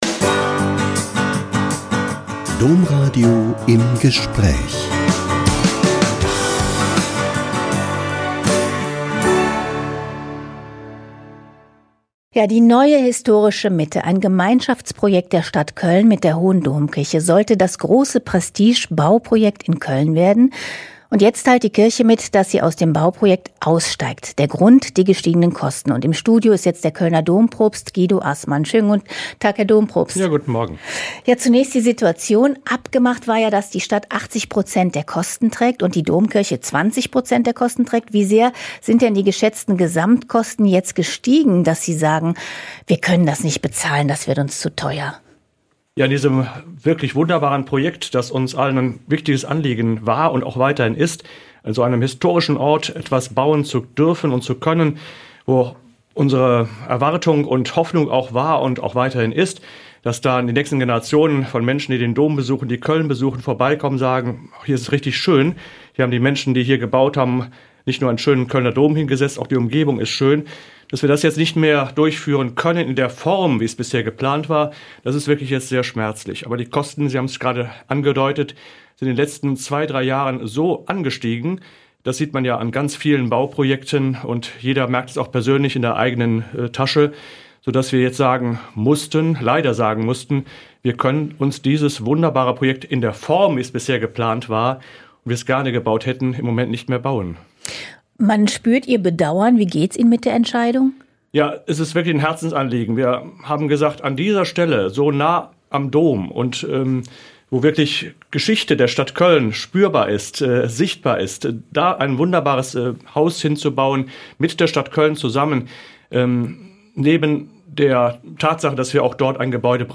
Kölner Dompropst bedauert Ausstieg bei "Historischer Mitte" - Ein Interview mit Monsignore Guido Assmann (Dompropst des Kölner Doms)